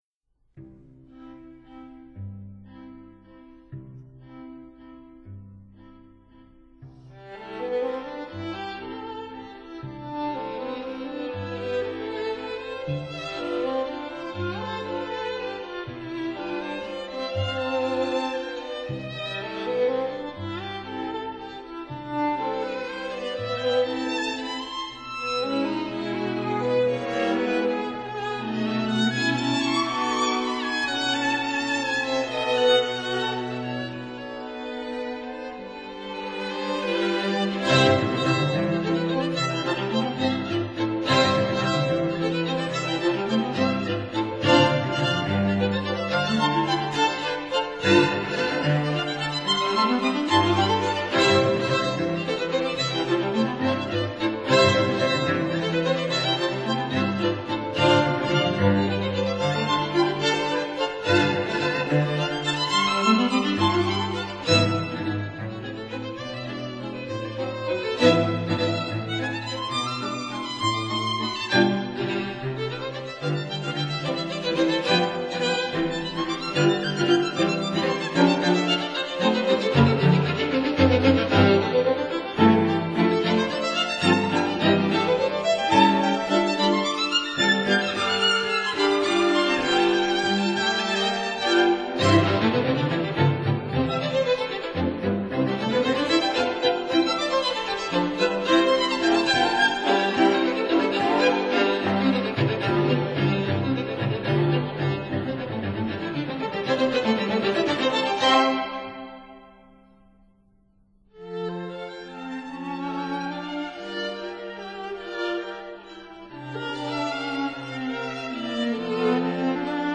violins
viola
cello